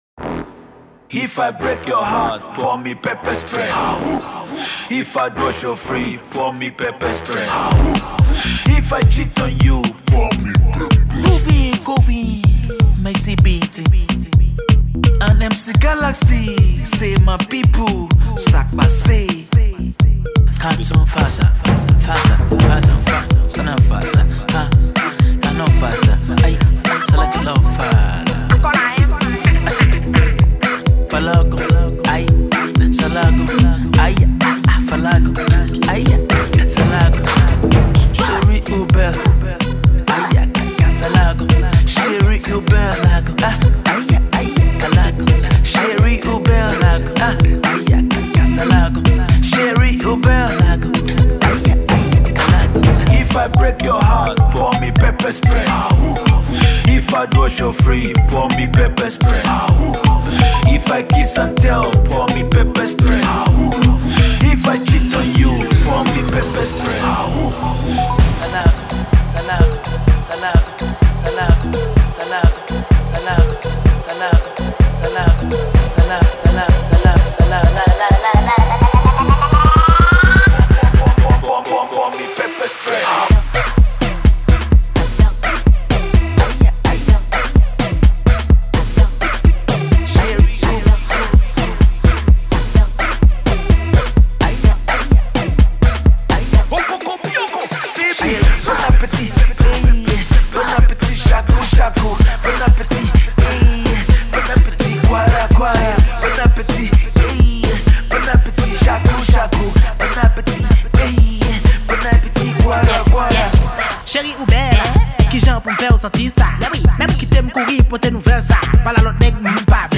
a dance song all the way and will definitely get you going.